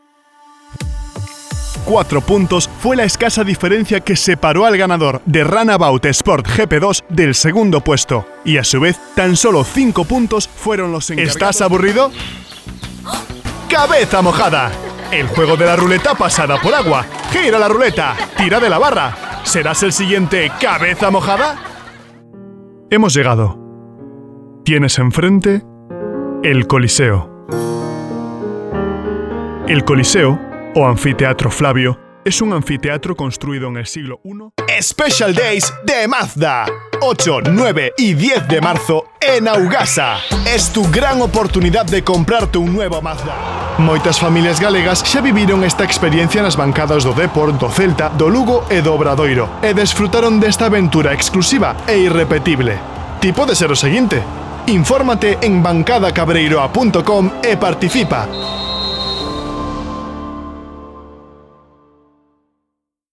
Estudio de grabación con acústica controlada y equipos profesionales como el micrófono Shure SM7B y la interfaz M-Audio.
Locutor español, castellano, gallego, voz joven, adulto, fresca, profesional, amigable, natural, corporativa, que no suene a locutor.
Sprechprobe: Sonstiges (Muttersprache):
Studio recording with controlled acoustics and professional equipment like Shure SM7B microphone and M-Audio interface .